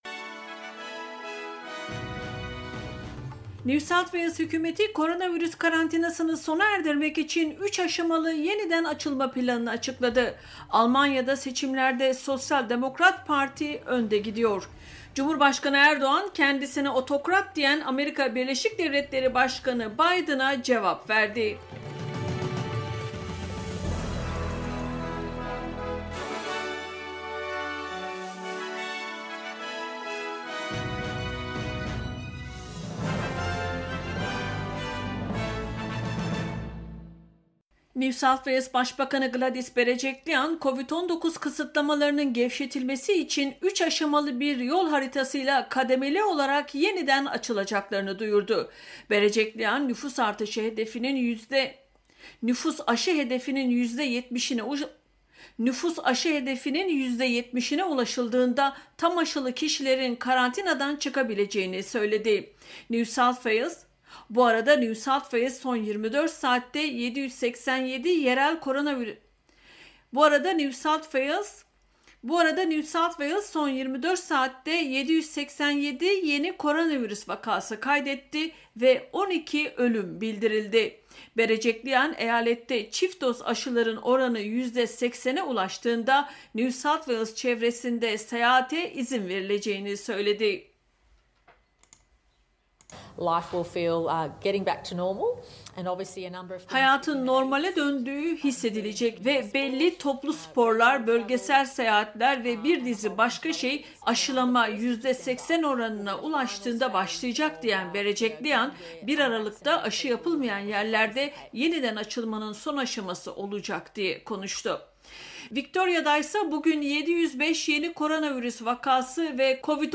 SBS Türkçe’den Avustralya, Türkiye ve dünyadan haberler.